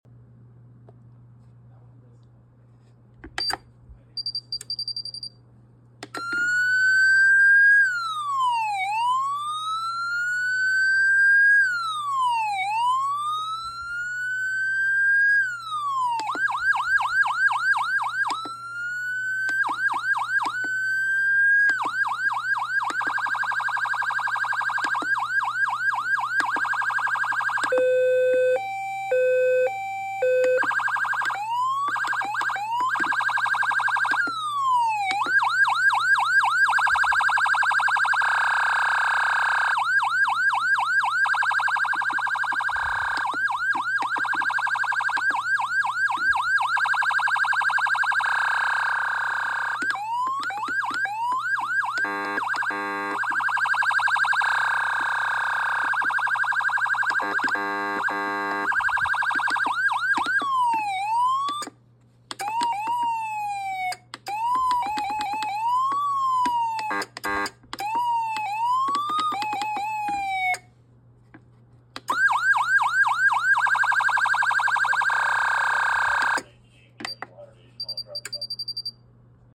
D&R F3.3000.S Intimidator Siren Demo, Sound Effects Free Download
D&R F3.3000.S Intimidator Siren Demo, brand new siren.